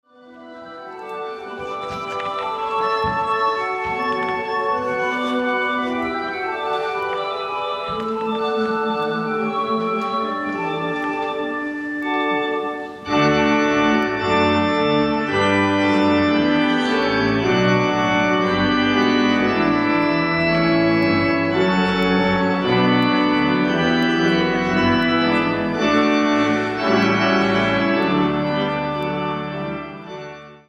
Historische Orgel in der Maria-Magdalenen-Kirche zu Lauenburg
... und so klingt die historische Orgel: